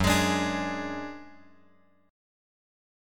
F#sus2#5 chord {2 x x 1 3 4} chord